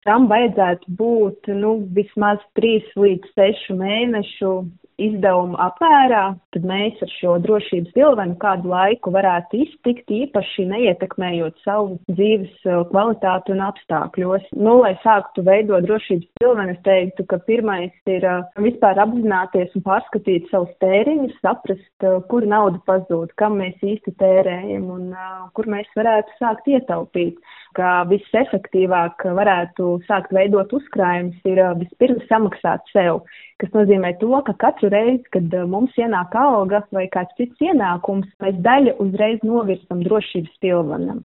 Kā sākt veidot savu “drošības spilvenu” to intervijā Skonto mediju grupai